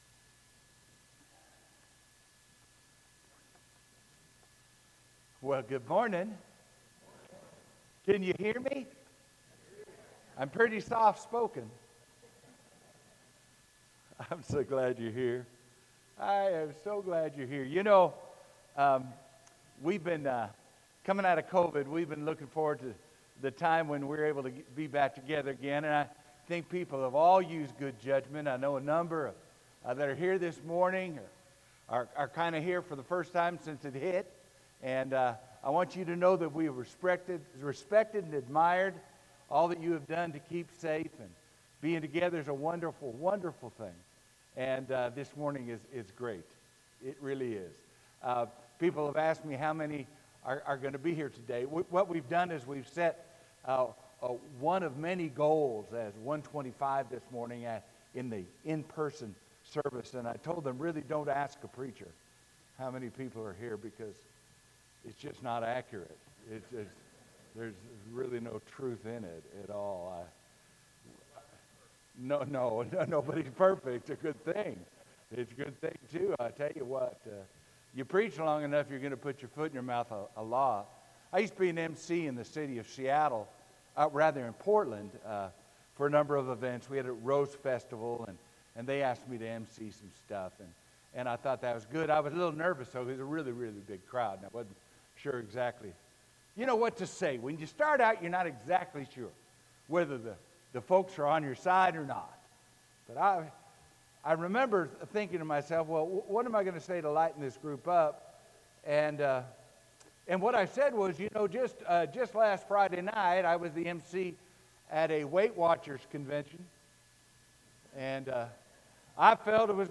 Sermon: Needing More Than Water – IGNITE-TRANSFORM-REFLECT-SHINE